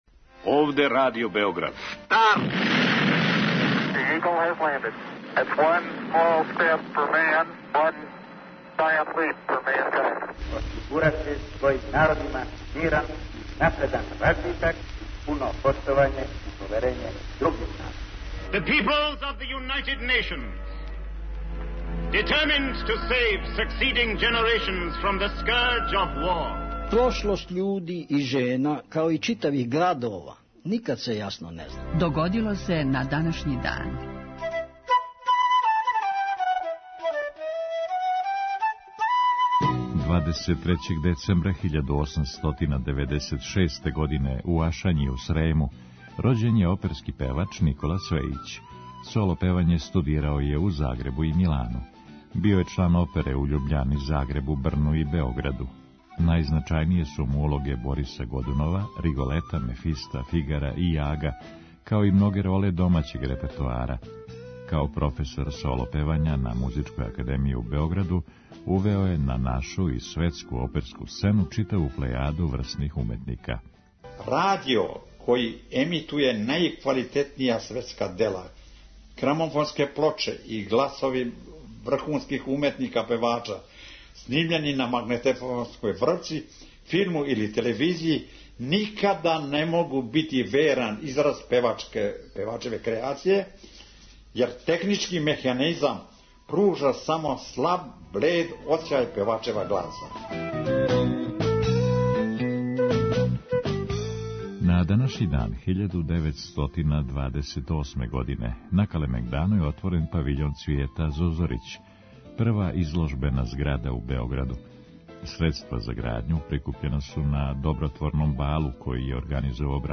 Емисија Догодило се на данашњи дан, једна од најстаријих емисија Радио Београда свакодневни је подсетник на људе и догађаје из наше и светске историје. У петотоминутном прегледу, враћамо се у прошлост и слушамо гласове људи из других епоха.